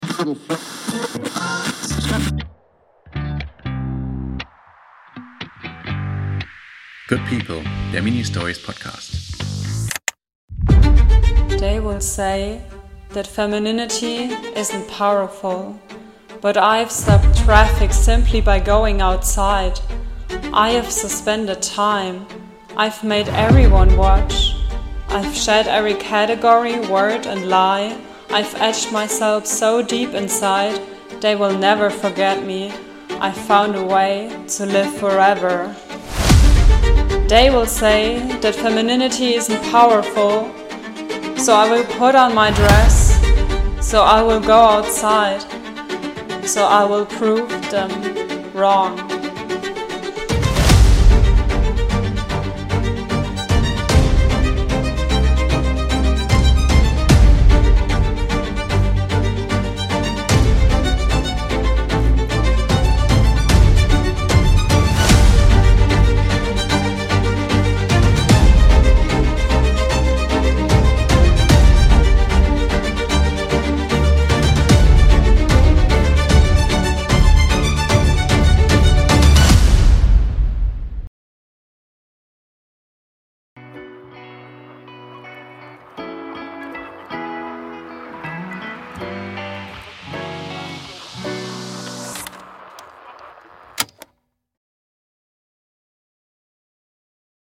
Soundcollagierend gehen wir auf den ersten CSD in Hildesheim, wir lesen Statistiken zu transfeindlicher Gewalt, wie immer weinend und dann chanten wir ein Gedicht von ALOK, um uns zu reminden, wie magical und powerful wir trans* people sind.